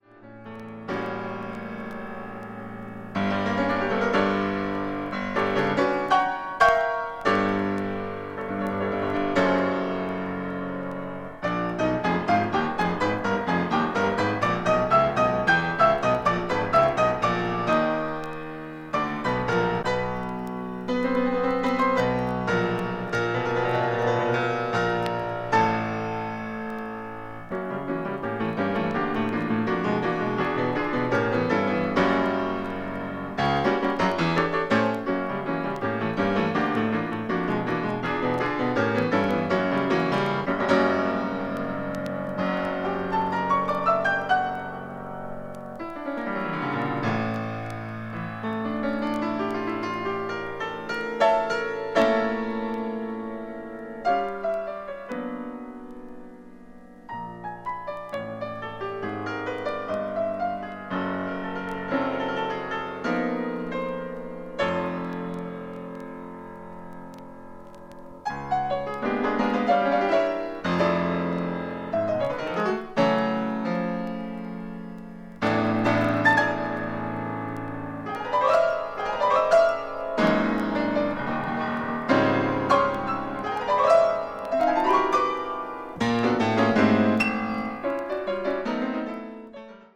20th century   contemporary   modern classical   piano